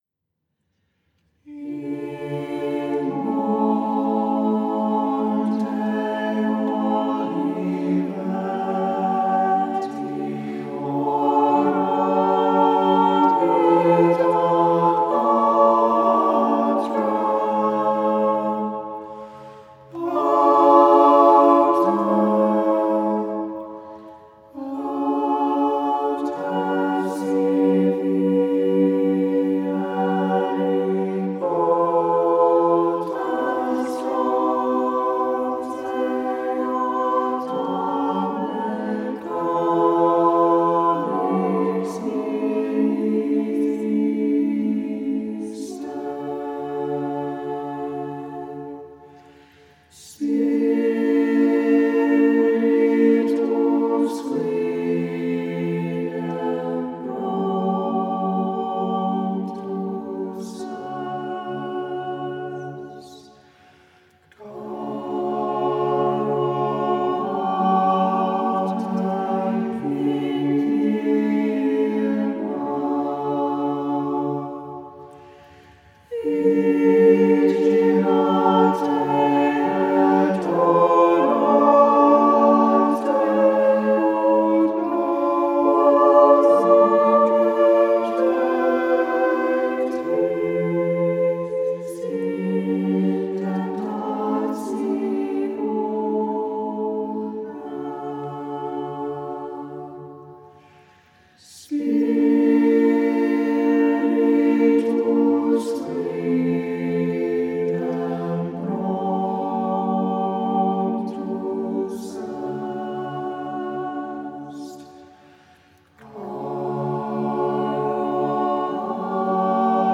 The mournful chant to which they are sung is probably the one used by the Jews themselves.
The Responsory to the First Lesson of the First Nocturn from Tenebrae on Holy Thursday
(You may listen to a recording of this Responsory, sung by the St. Isidore choir,